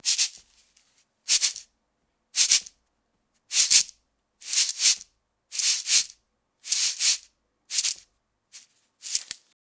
African Shekere 3.wav